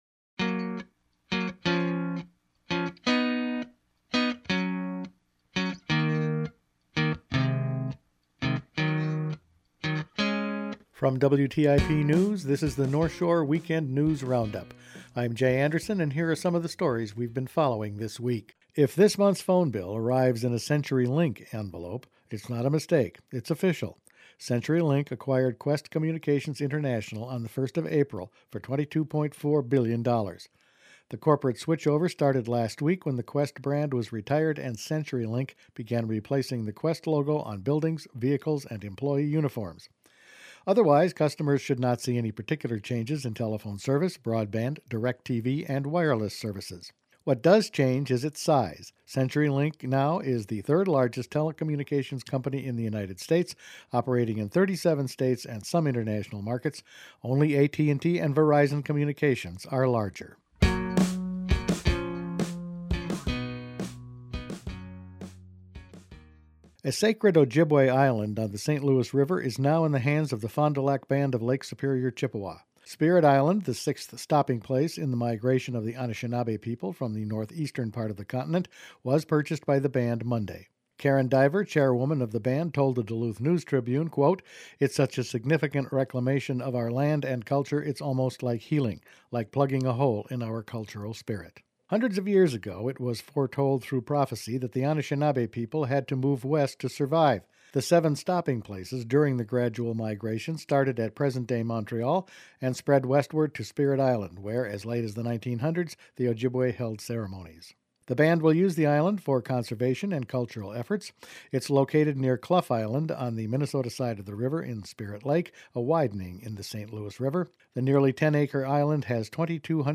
Weekend News Roundup for Aug. 20
Each weekend WTIP news produces a round up of the news stories they’ve been following this week. For many of us Qwest is now CenturyLink, the Fond du Lac Band bought an island, a new moose management plan has been floated and the budget for IRRRB will fall for 2012…all in this week’s news.